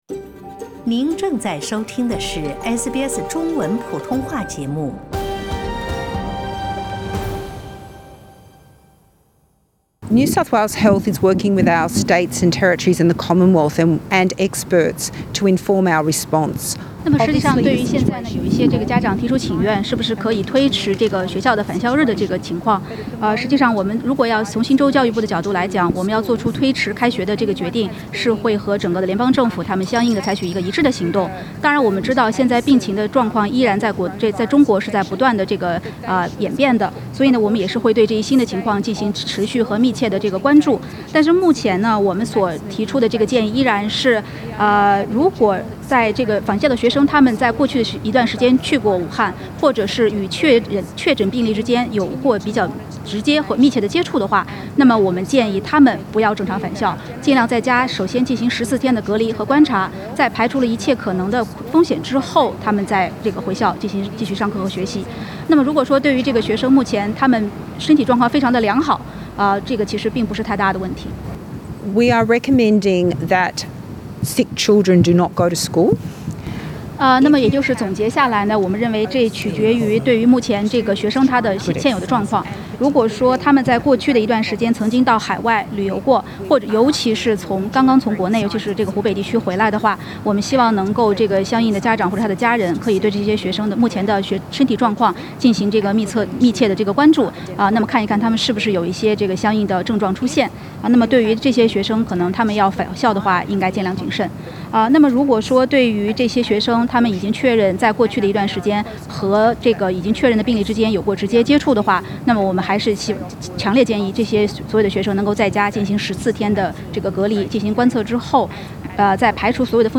新州卫生部首席卫生官Kerry Chant博士对SBS普通话节目表示，目前新州不会延迟开学时间。同时她也指出，任何与确诊感染新型冠状病毒的人接触过的孩子，在与感染者最后一次接触后的14天内不得上学或入托。